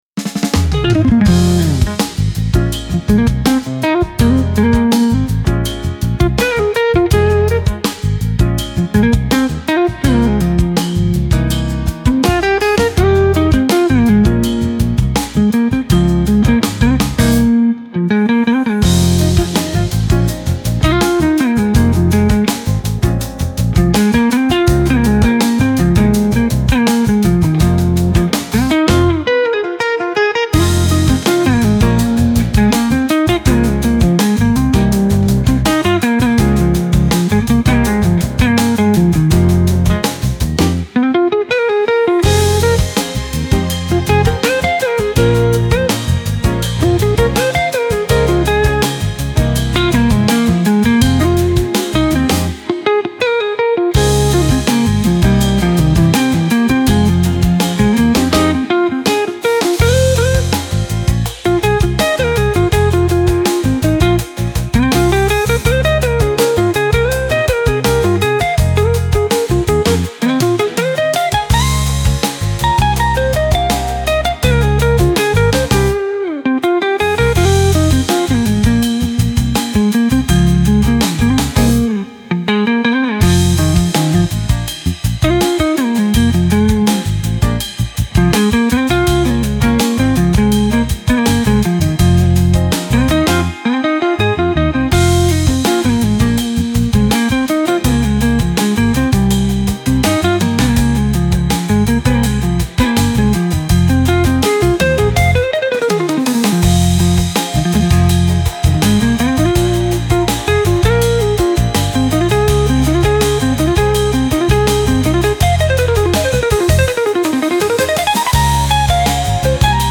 like-jazz-funk-joo.mp3